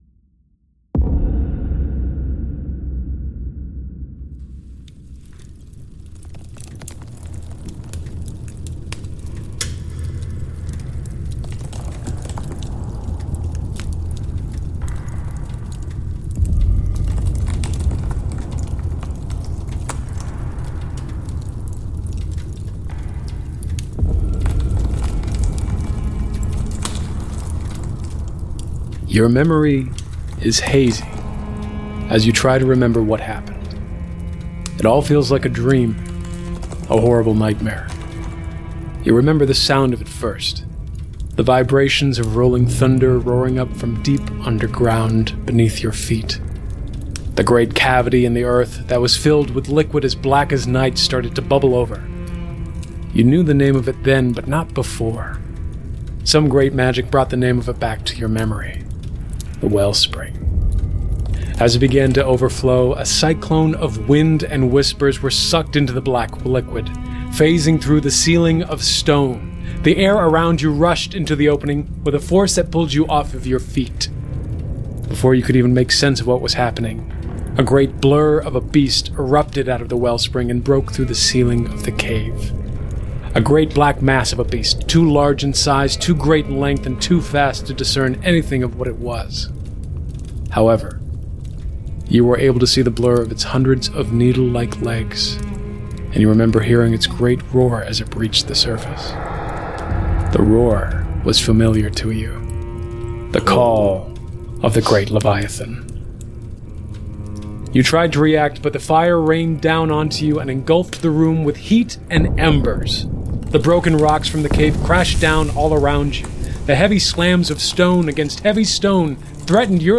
An actual-play tabletop RPG podcast that's held together with fantasy duct tape, cybernetic servos and the suspension of disbelief.